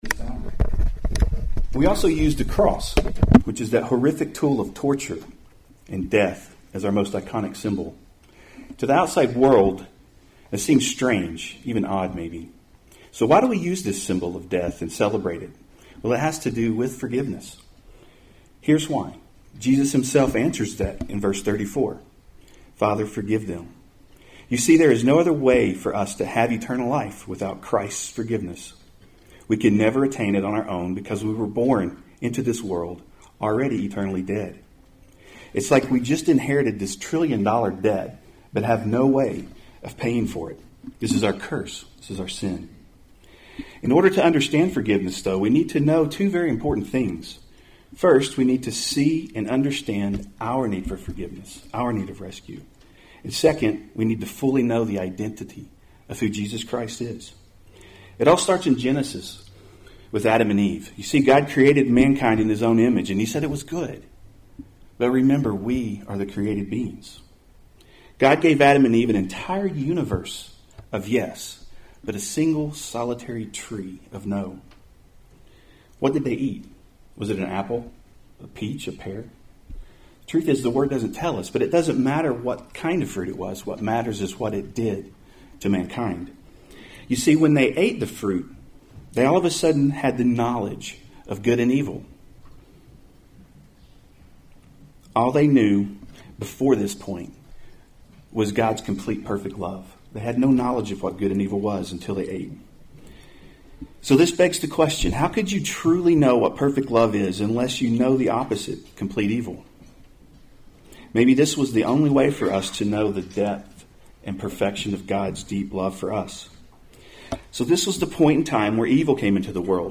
March 30, 2018 Special Services series Good Friday Service Save/Download this sermon Various Scriptures Other sermons from Various Scriptures This is a recording of our Good Friday Tenebrae service.
All content in between is included, though some may be difficult to hear due to the location of the recording device.